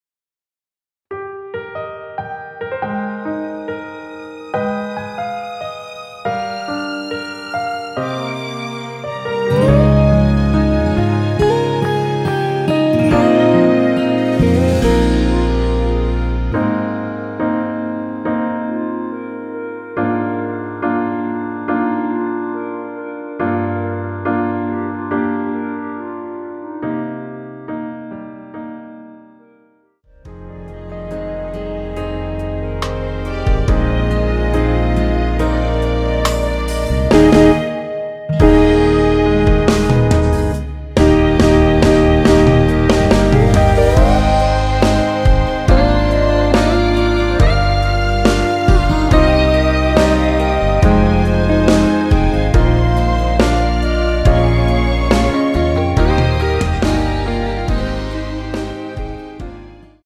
원키 멜로디 포함된 (1절앞+후렴)으로 진행되는 MR입니다.
Eb
앞부분30초, 뒷부분30초씩 편집해서 올려 드리고 있습니다.
중간에 음이 끈어지고 다시 나오는 이유는